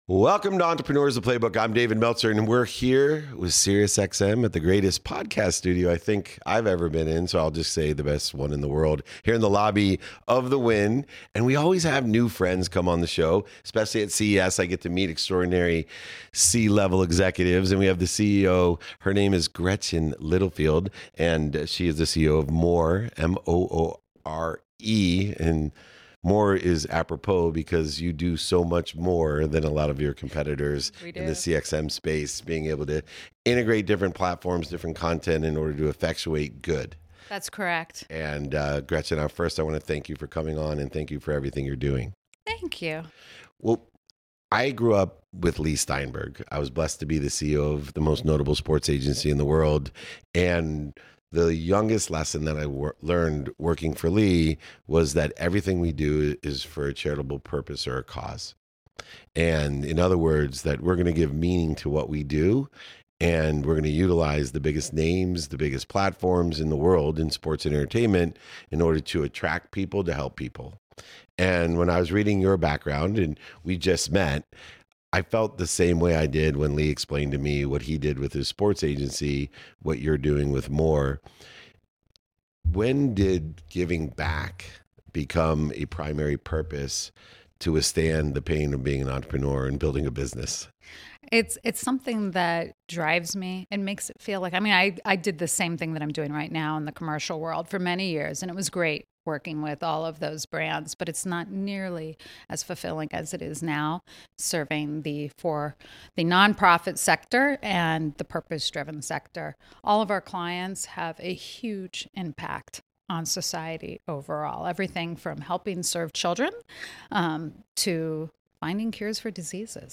This conversation reveals how innovation in data is transforming lives and making the world a better place.